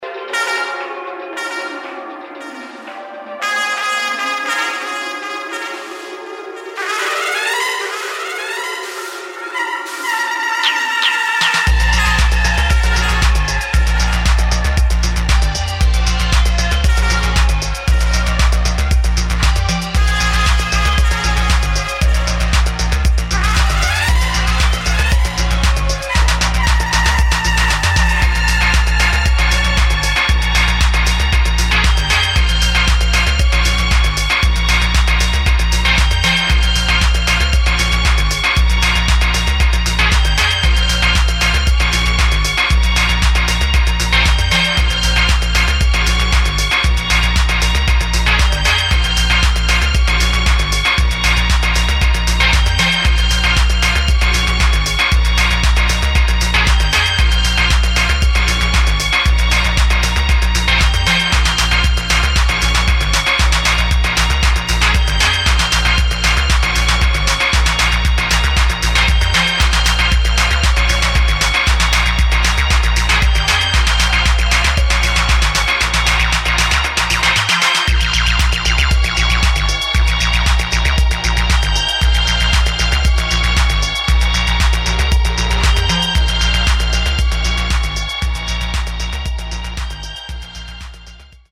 [ TECHNO | ELECTRO | DISCO ]